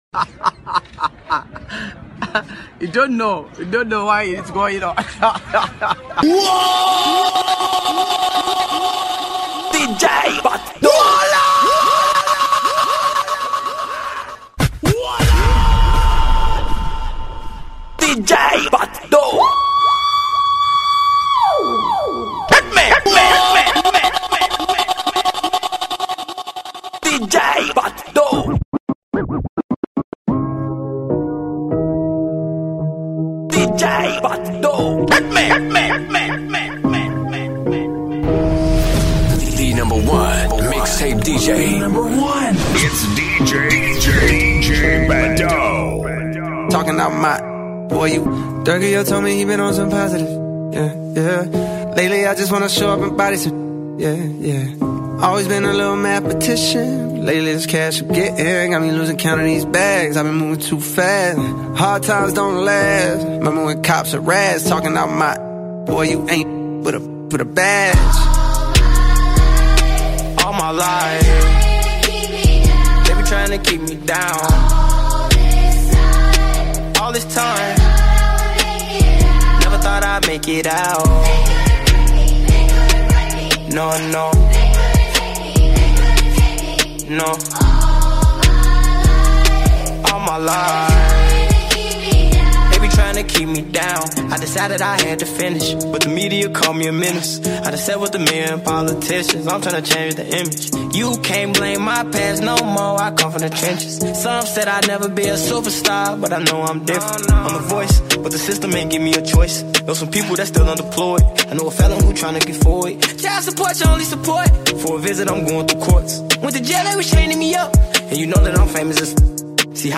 Nigerian disc jockey